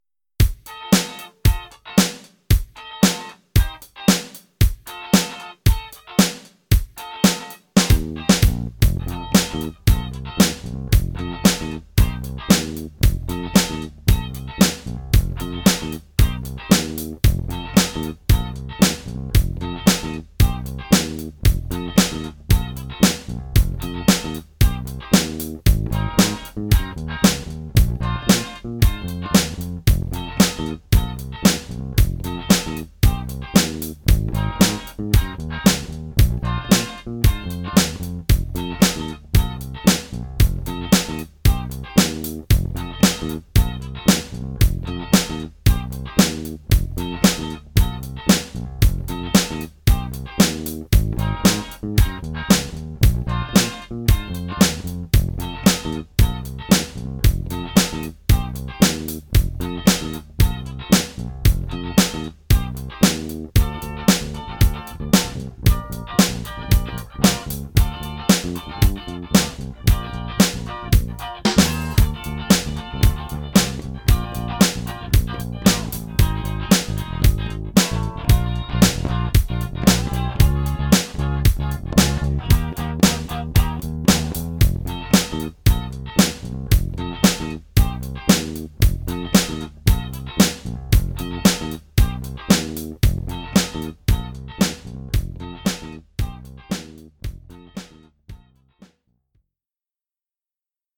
Funk
A quick funky groove inspired by Tower of Power.